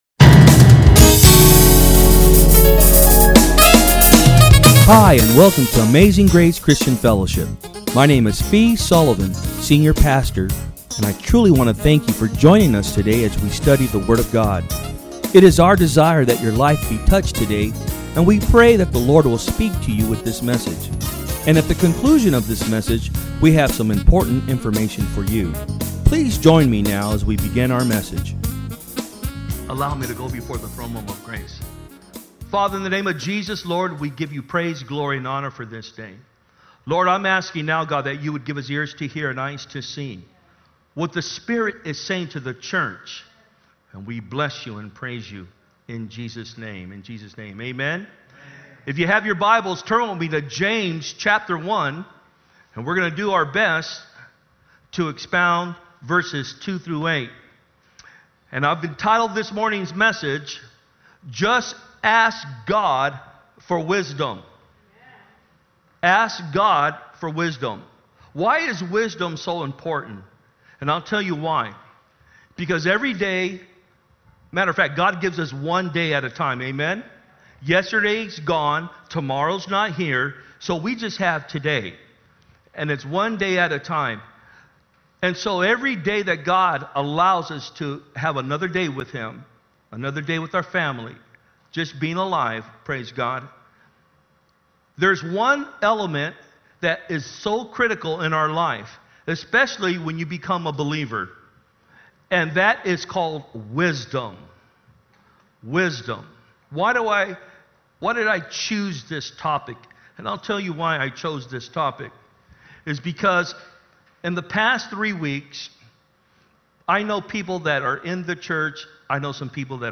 From Service: "Sunday Am"